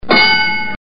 clang